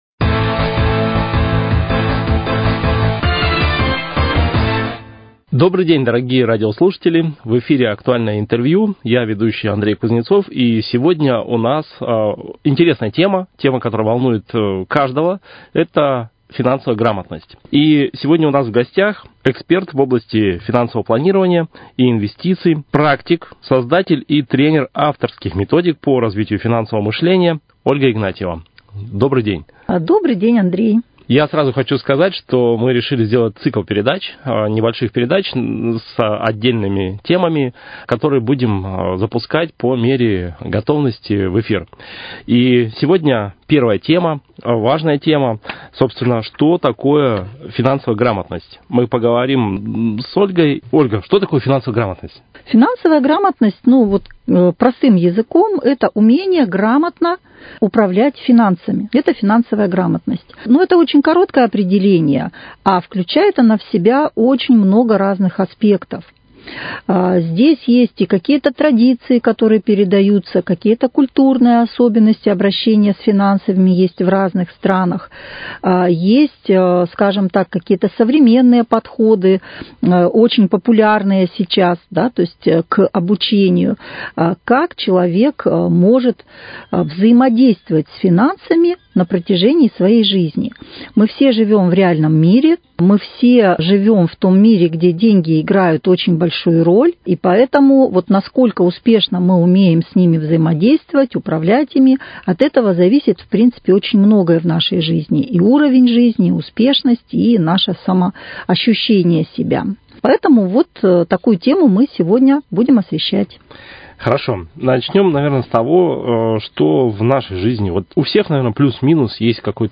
Актуальное интервью